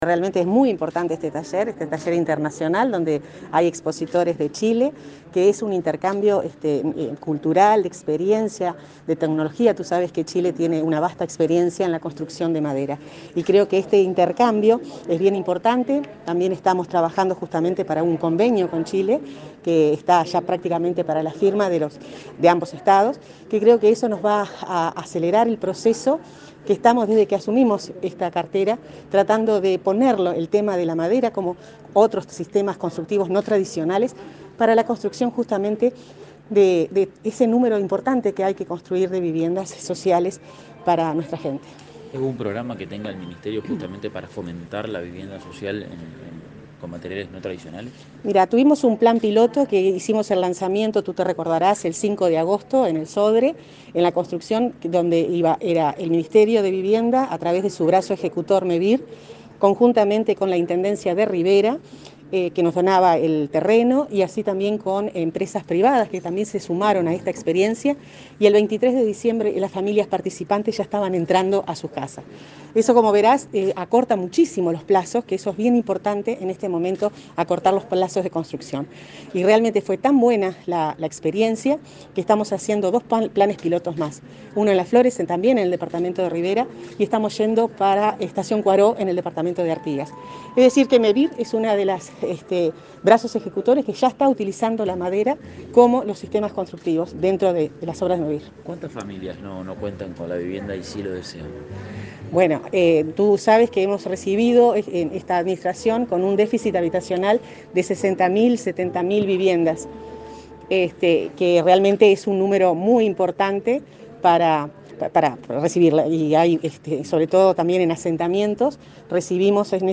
Declaraciones a la prensa de la ministra de Vivienda, Irene Moreira
La ministra de Vivienda, Irene Moreira, dialogó con la prensa luego de participar de la apertura del Taller Internacional de Normativas para la